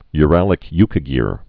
(y-rălĭk-ykə-gîr)